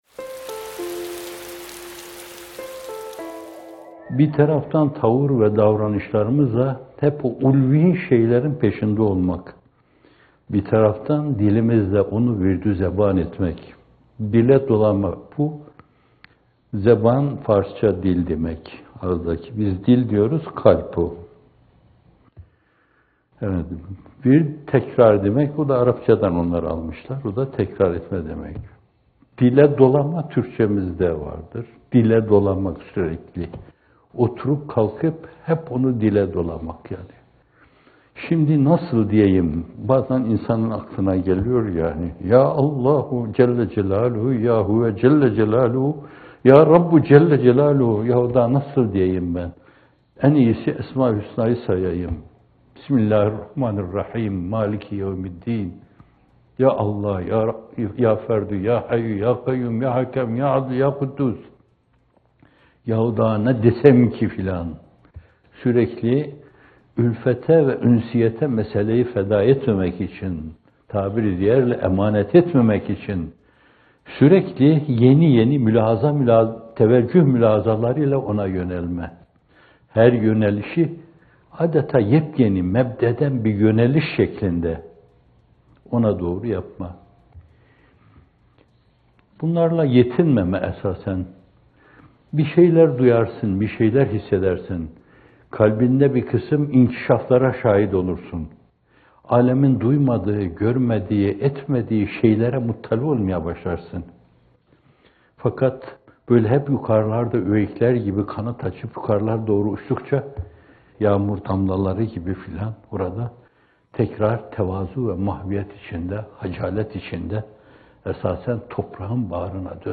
İkindi Yağmurları – Rabbim Sana Döndüm Yüzüm - Fethullah Gülen Hocaefendi'nin Sohbetleri